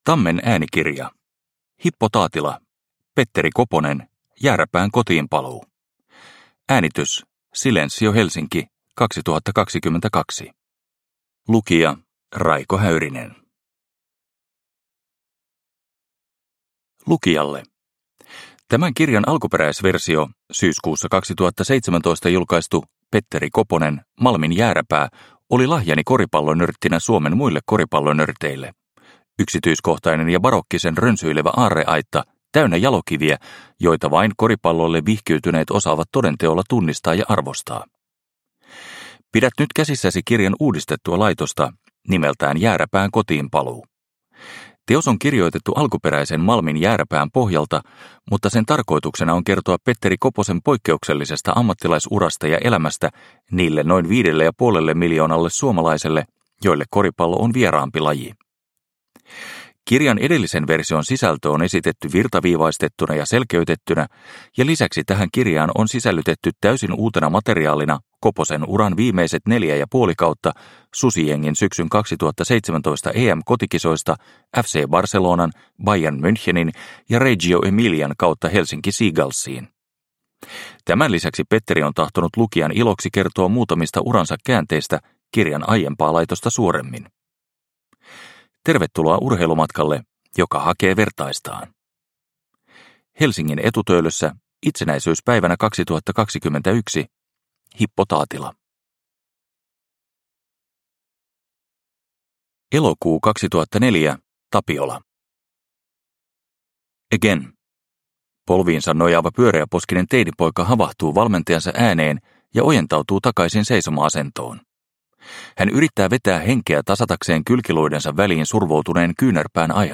Petteri Koponen - Jääräpään kotiinpaluu – Ljudbok – Laddas ner